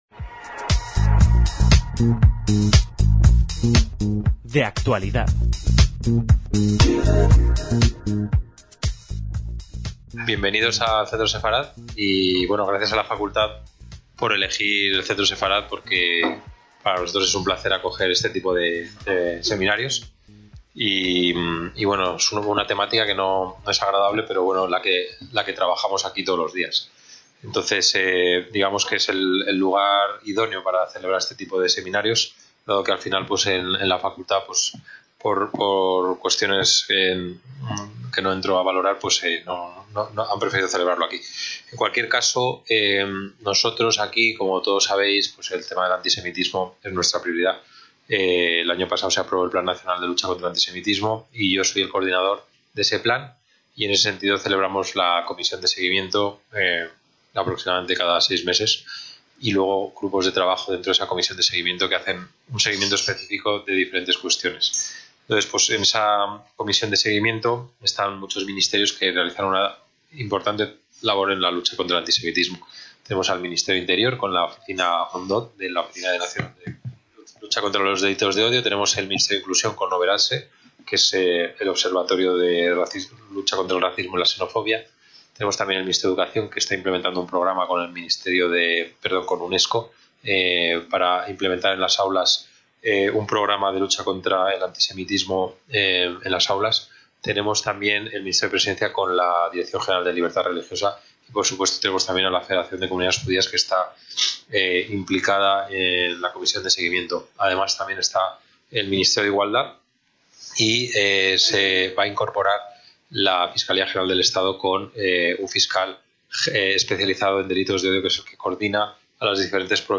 Presentación del VII Seminario Internacional contra el Antisemitismo (Centro Sefarad Israel, Madrid, 28/11/2024)